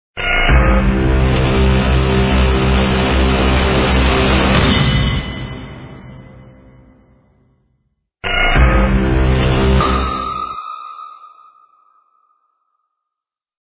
Section#2-Jingles, music logos
All tracks encoded in mp3 audio lo-fi quality.